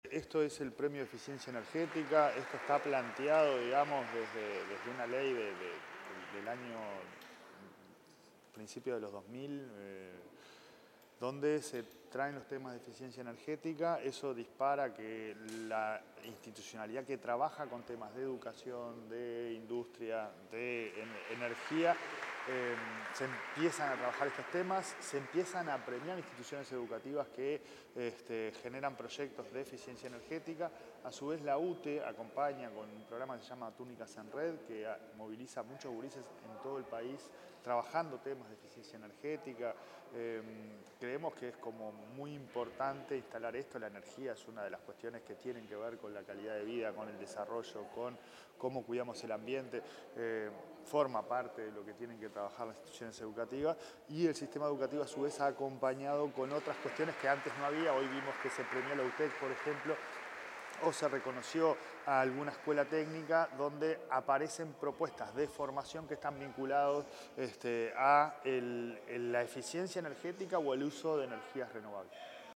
Declaraciones del presidente de la ANEP, Pablo Caggiani
Declaraciones del presidente de la ANEP, Pablo Caggiani 28/11/2025 Compartir Facebook X Copiar enlace WhatsApp LinkedIn En el marco de la ceremonia de la ceremonia de Entrega del Premio Nacional de Eficiencia Energética, el presidente de ANEP, Pablo Caggiani, realizó declaraciones.